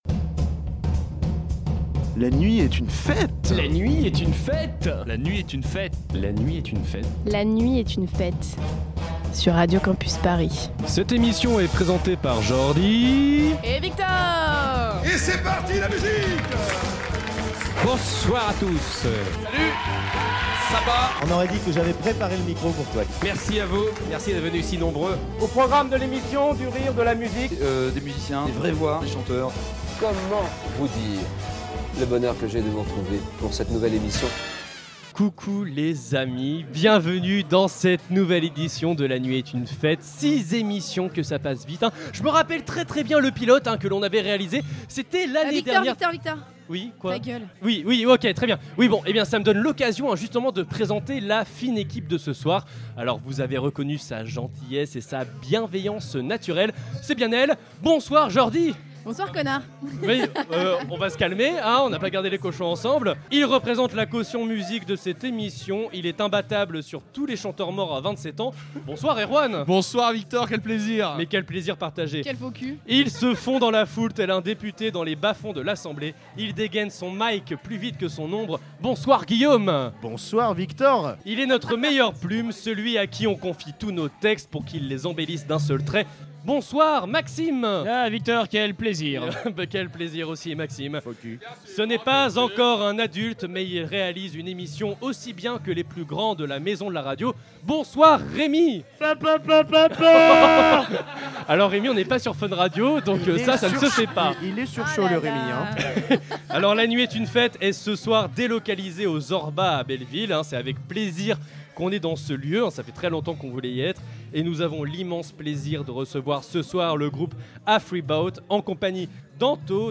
Pour ce sixième numéro, La Nuit Est Une Fête est délocalisée au Zorba, à Belleville, et nous avons eu l’immense plaisir de recevoir le groupe Afreeboat.